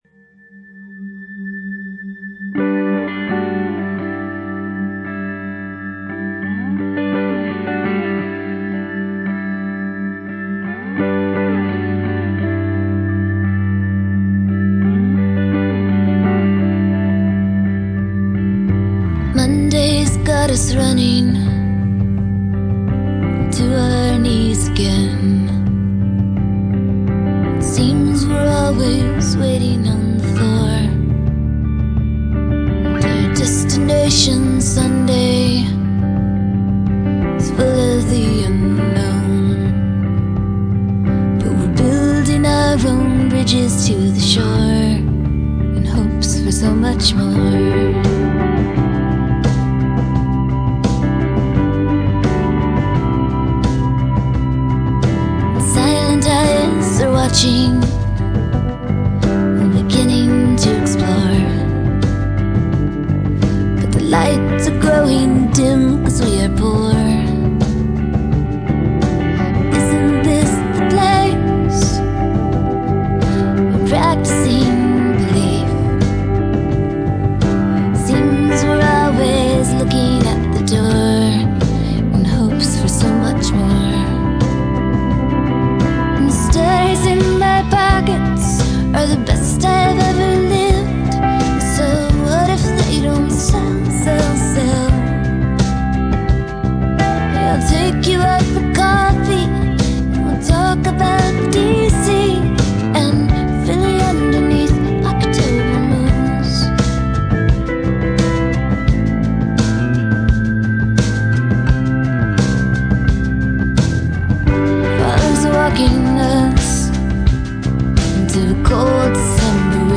With an almost folk-rock sound